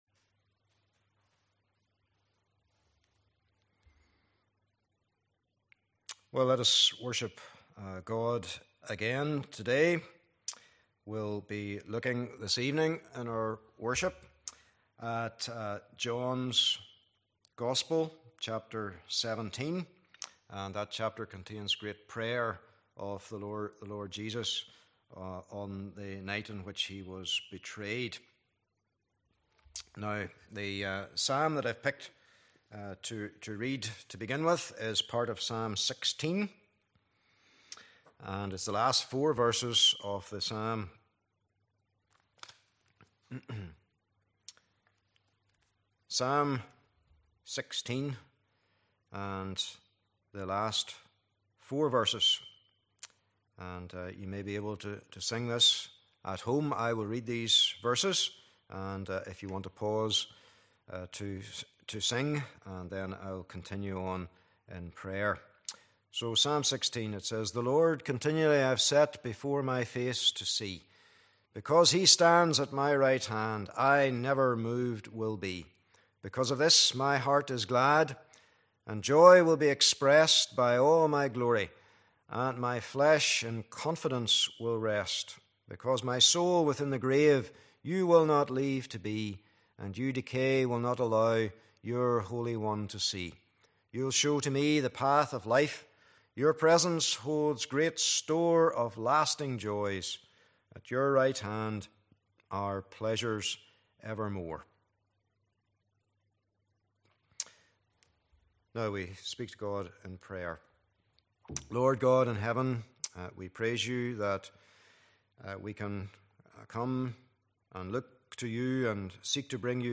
12 Service Type: Evening Service Bible Text